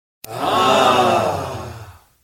男人起哄音效免费音频素材下载